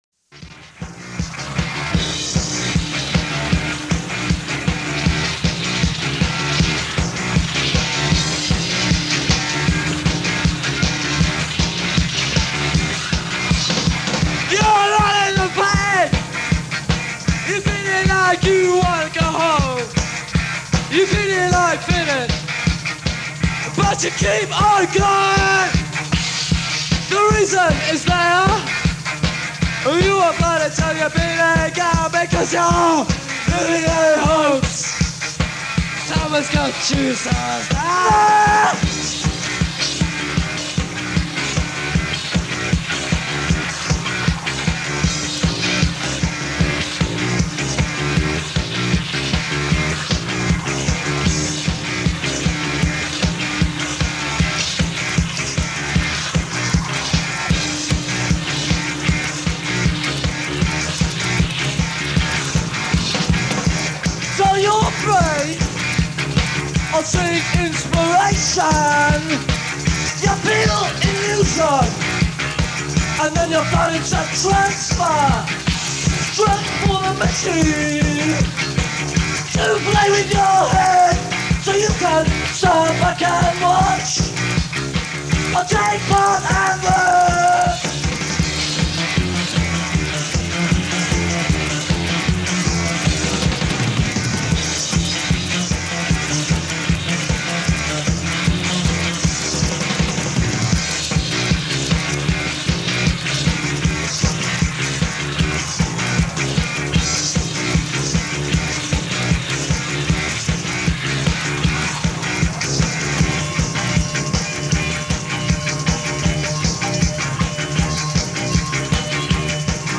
Band soundboard.